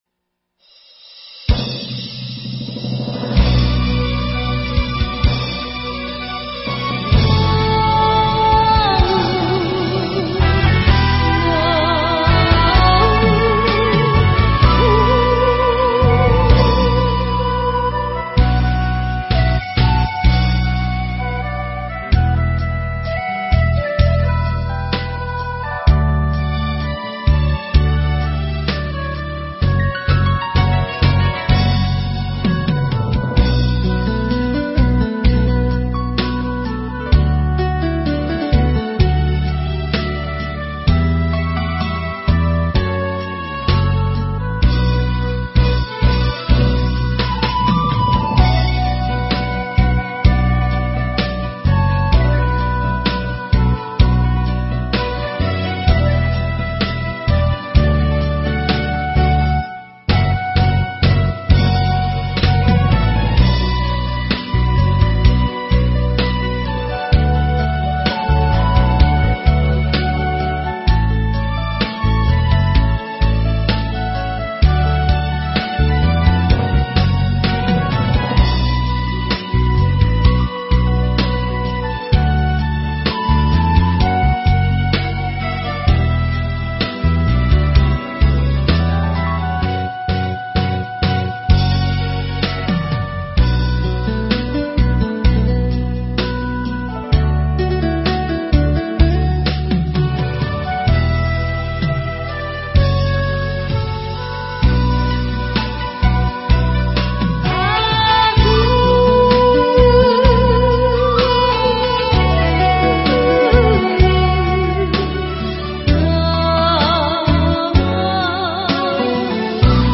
* 링크주소를 클릭하시면 반주곡 미리듣기를 하실 수 있습니다.